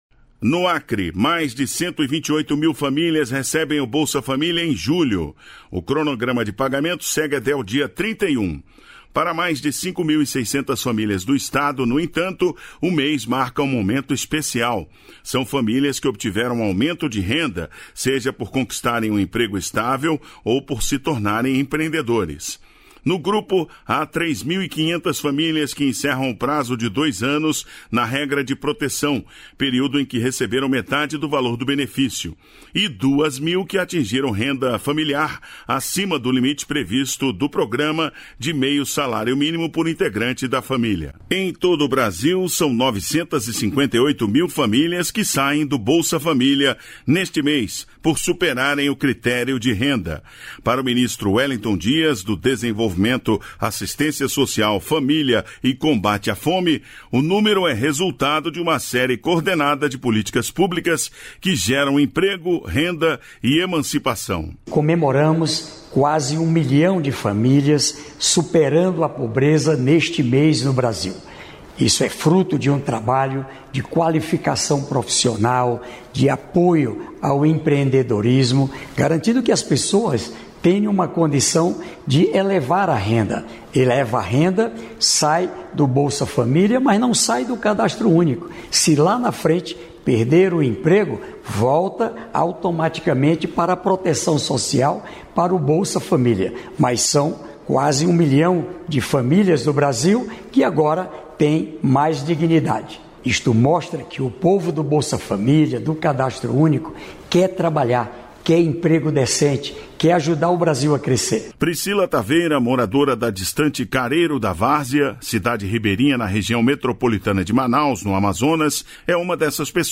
A Diretora Socioambiental do BNDES, Tereza Campello, comenta a aprovação do primeiro projeto da chamada pública Amazônia na Escola: Comida Saudável e Sustentável. Intitulado Nosso Paneiro, o projeto receberá R$ 24 milhões do Fundo Amazônia e será implementado no Acre.